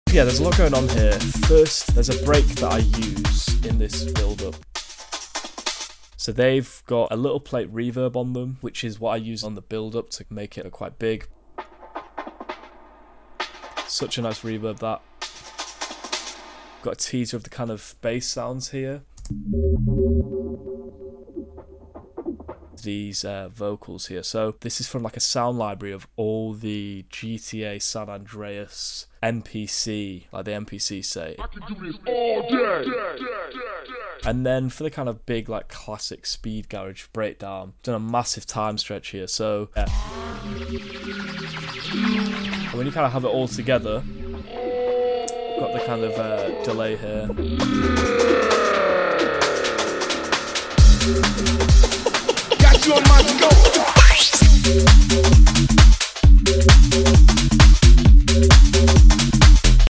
Using plate reverb to create space, teasing the bass early, and even sampling GTA San Andreas NPC vocals for character. A huge time stretch brings that classic speed garage breakdown together.